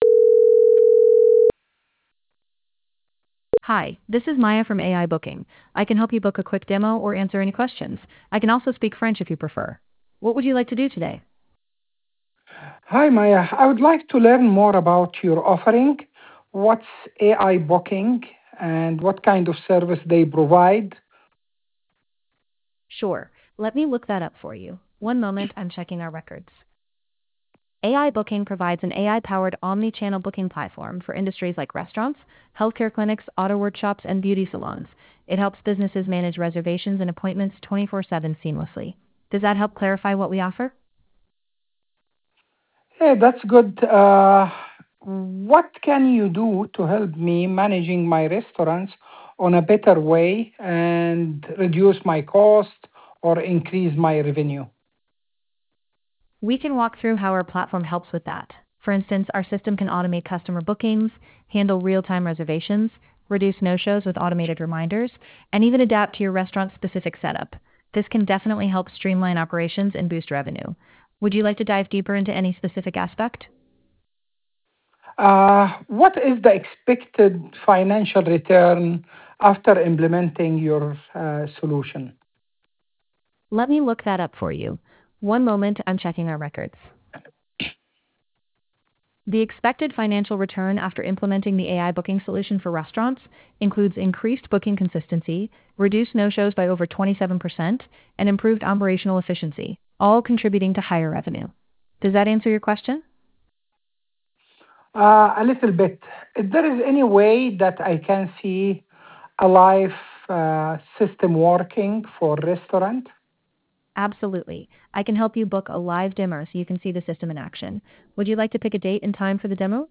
AI Real Call
two minutes voice • booking a demo with us
AI-Booking-Confirmation-Voice.wav